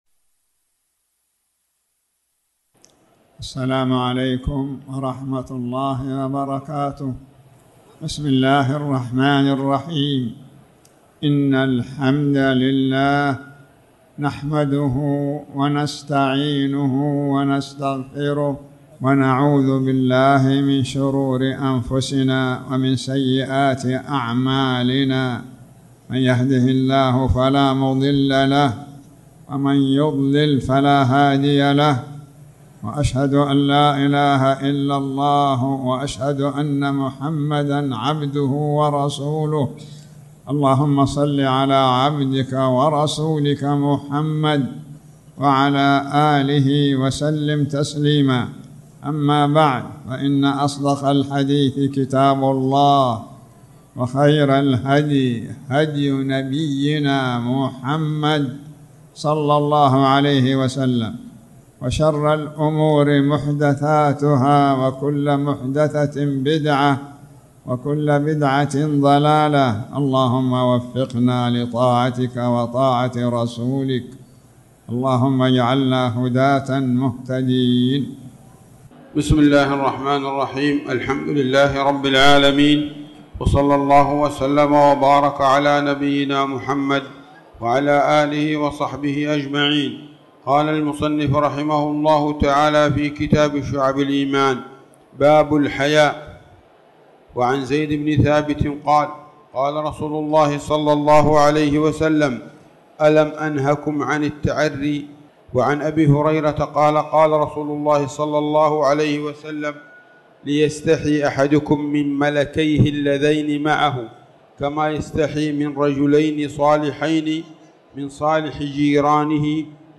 تاريخ النشر ٢٧ ربيع الثاني ١٤٣٩ هـ المكان: المسجد الحرام الشيخ